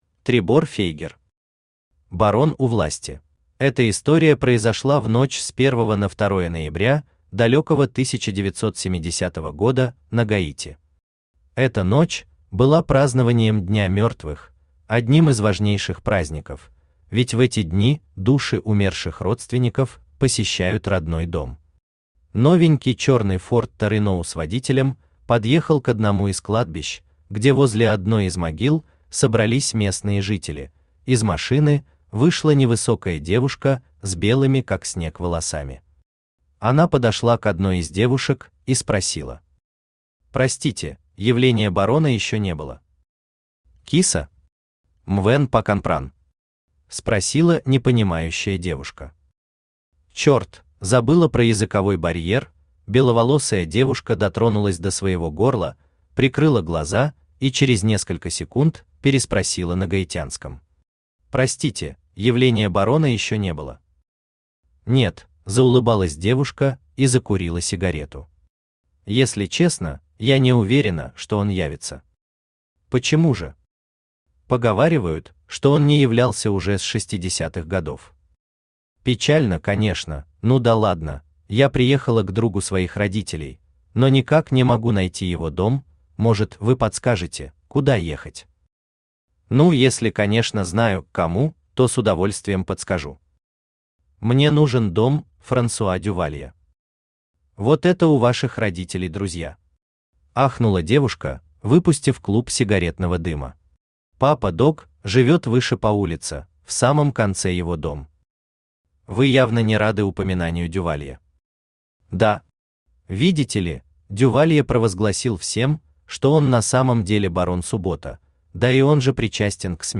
Аудиокнига Барон у власти | Библиотека аудиокниг
Aудиокнига Барон у власти Автор Требор Фейгер Читает аудиокнигу Авточтец ЛитРес.